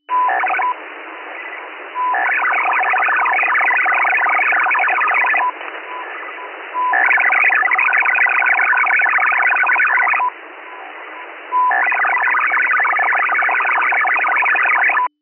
CHINESE MIL ROBUST 125 Bd 8-FSK WAVEFORM (same tone library as MIL-STD 188-141B 2G ALE) AUDIO SAMPLES Chinese MIL robust 8-FSK waveform sending traffic bursts Chinese MIL robust 8-FSK waveform with control sequences and hybrid 2400 Bd BPSK modem back to MFSK-systems page